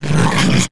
hit_0.ogg